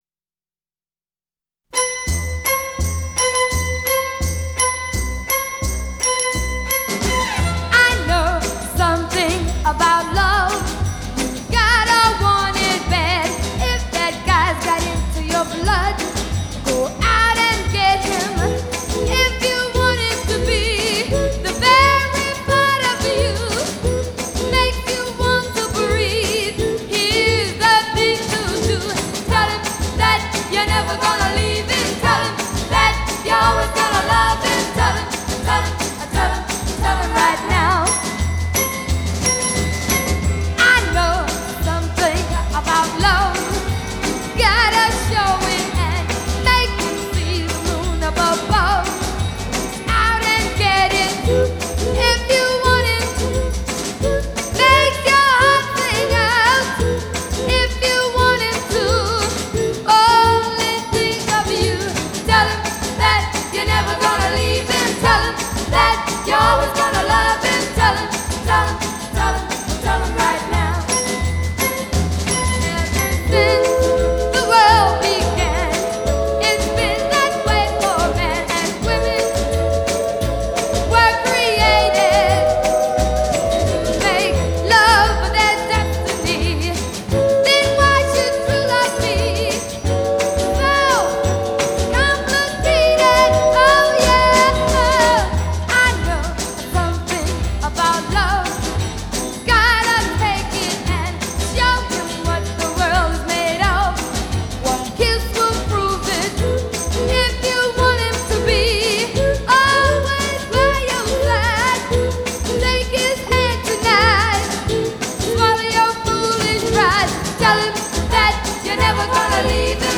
Genre: Pop, Rock & Roll, Beat